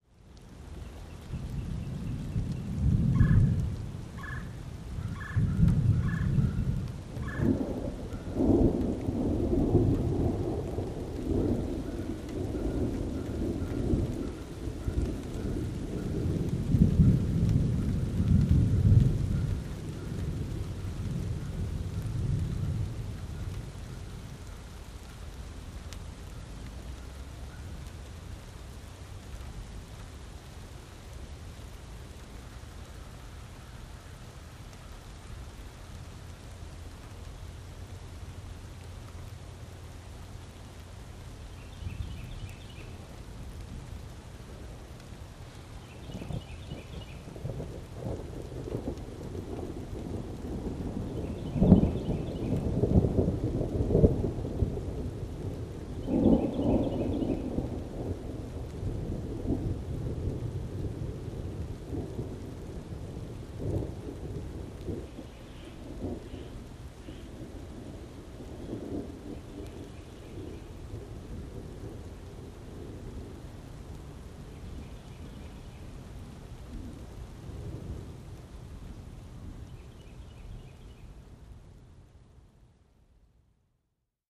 am_rain_thunder_04_hpx
Thunder rumbles over light rain as birds chirp in background. Rain, Thunderstorm Storm Weather, Thunderstorm